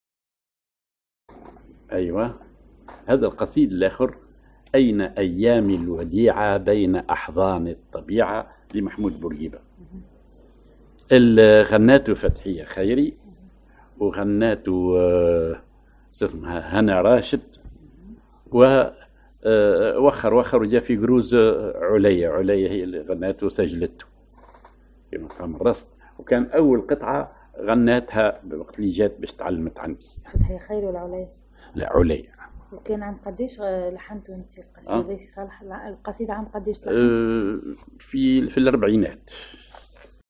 Maqam ar راست
Rhythm ar الوحدة
genre أغنية